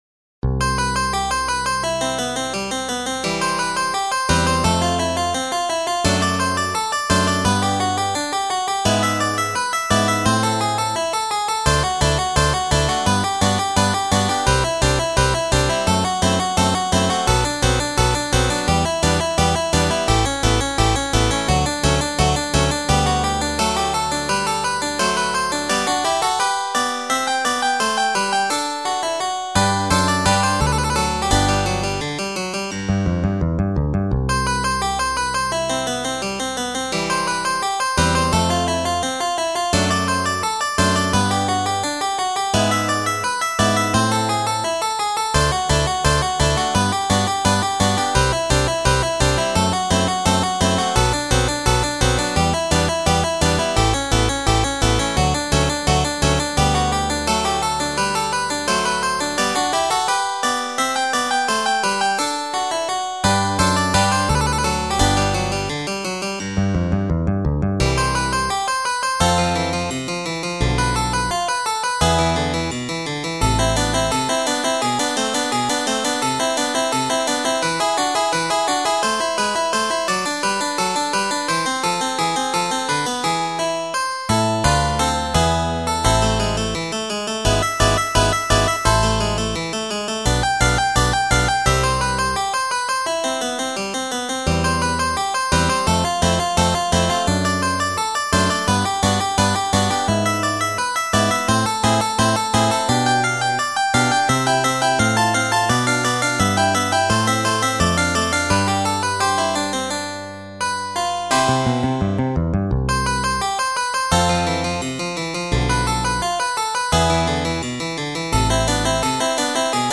Organ  (View more Intermediate Organ Music)
Classical (View more Classical Organ Music)